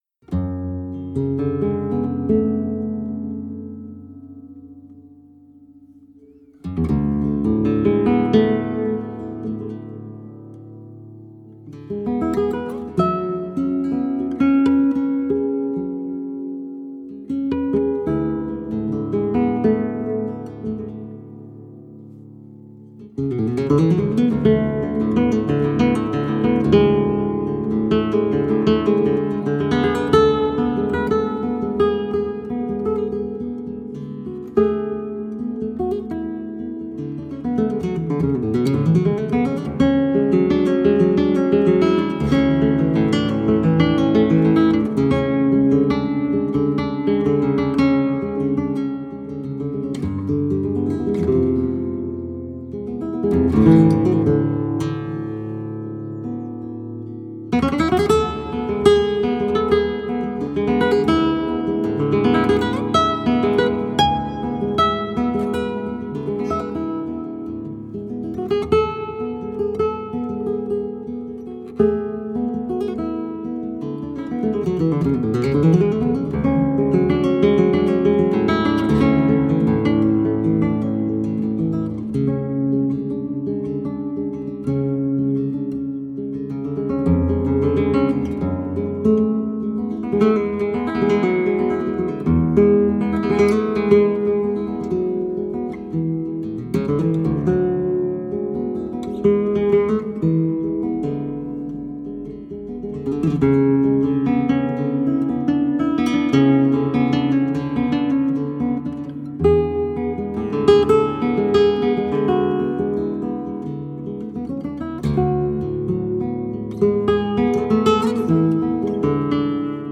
composition et guitare